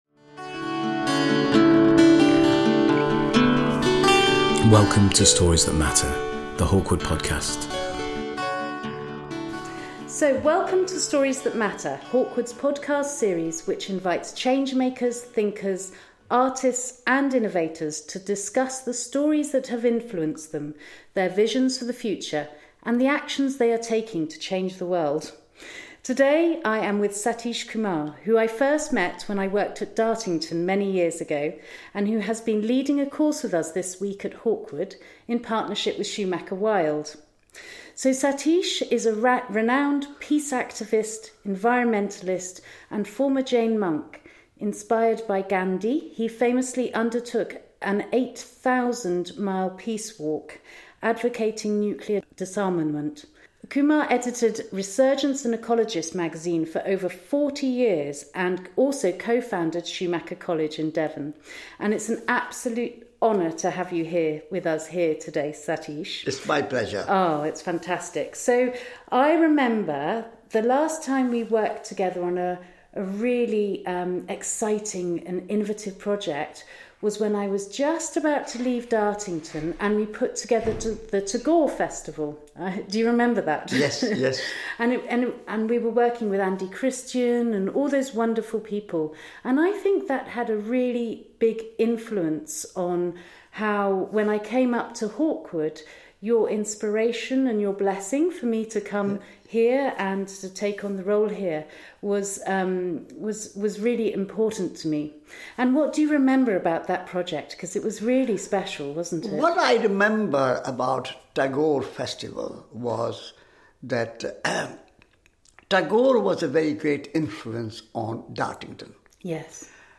This was recorded whilst Satish was at Hawkwood hosting his Radical Love course in partnership with Schumacher Wild.